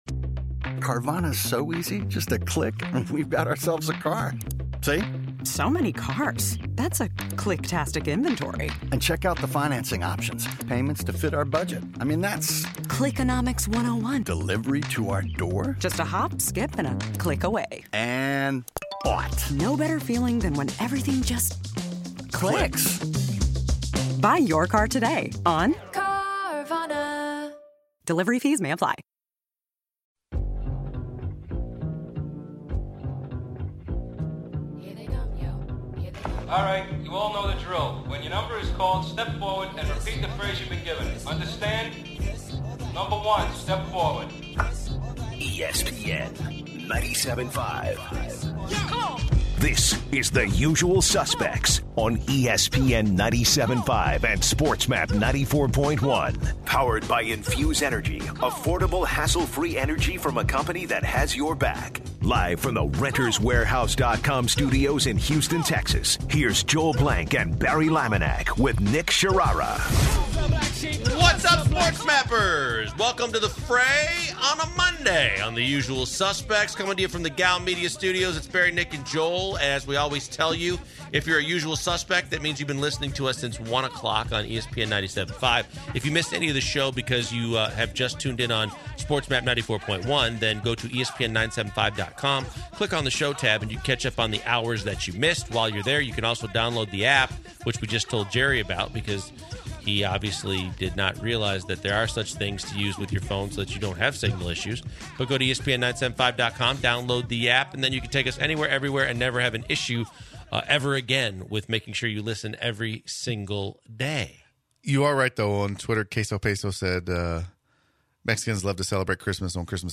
The guys take a call and answer some questions about the upcoming Saints game tonight and whether or not the Panthers have a shot at an upset. The guys take calls about the upcoming NFL matchups in the last two weeks and get around to discussing the Texans game due to be played against Philly.